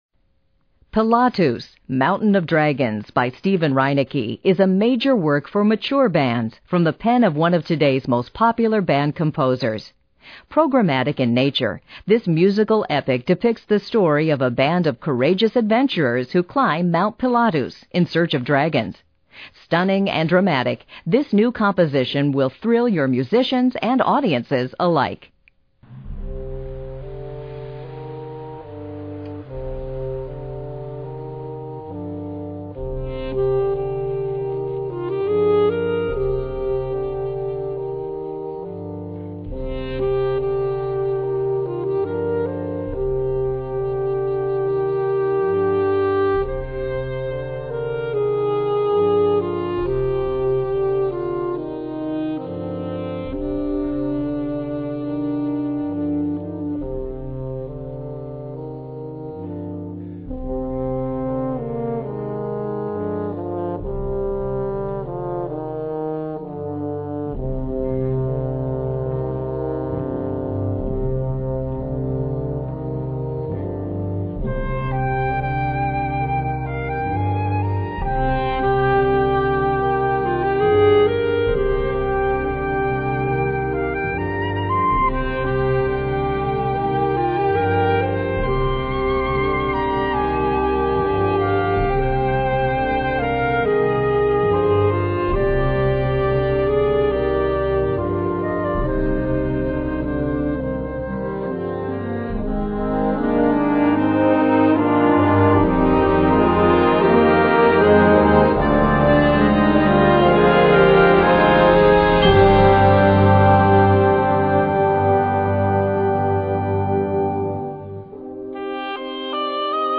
Categorie Harmonie/Fanfare/Brass-orkest
Subcategorie Programmatische muziek
Bezetting Ha (harmonieorkest)
De muziek wordt zachter, harmonieuzer en straalt vrede uit.